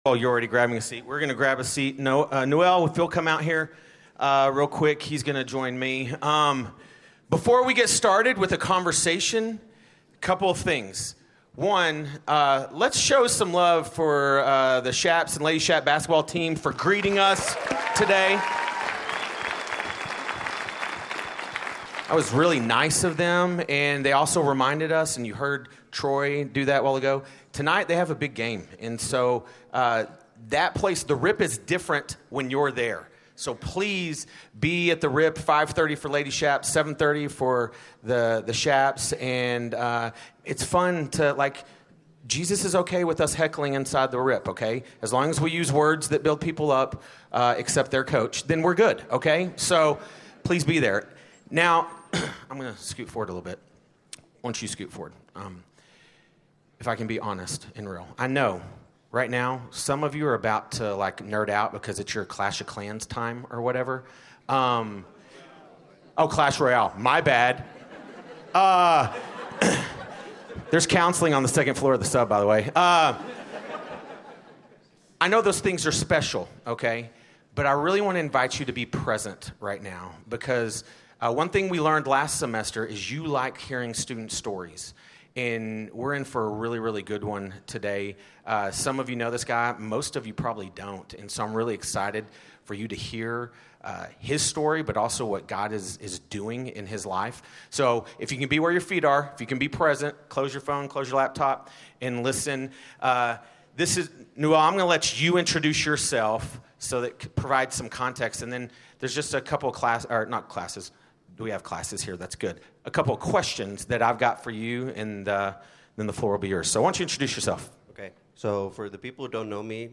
Monday, Tuesday, Thursday, and Friday in the McDonald Moody auditorium, campus family and friends make time for chapel, a time to celebrate relationships.